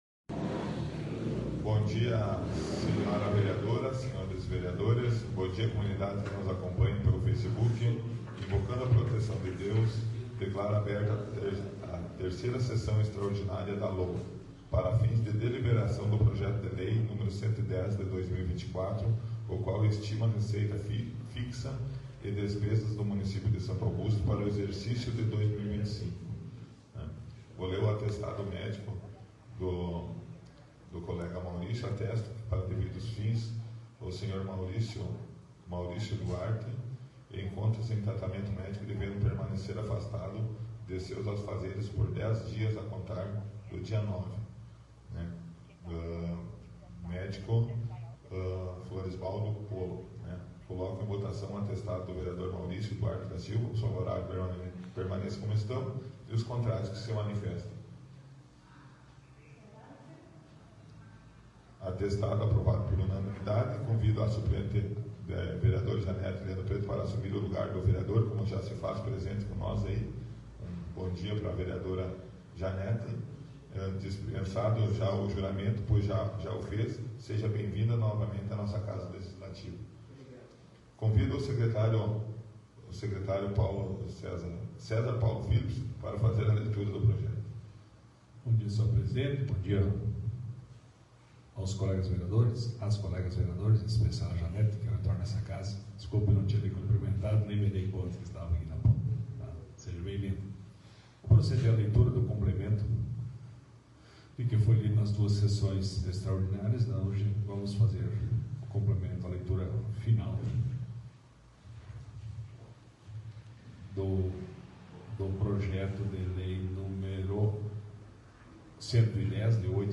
10ª Extraordinária da 4ª Sessão Legislativa da 15ª Legislatura